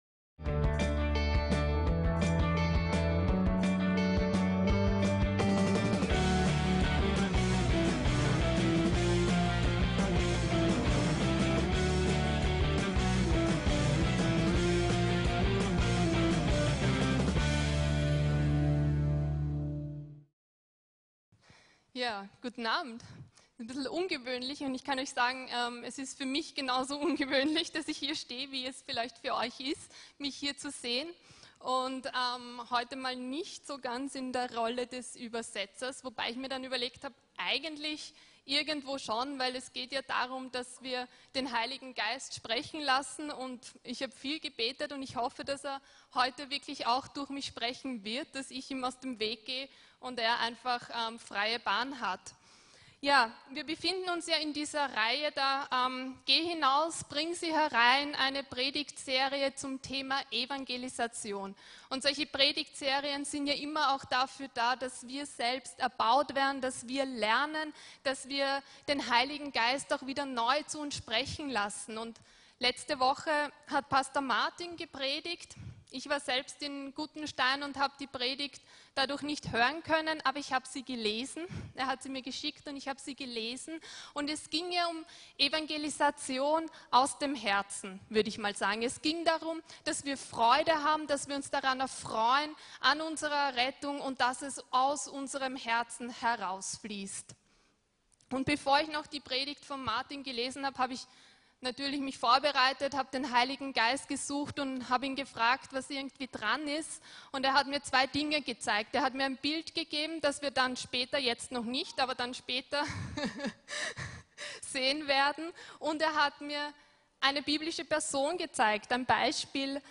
LASS DEINE GLOCKEN LÄUTEN ~ VCC JesusZentrum Gottesdienste (audio) Podcast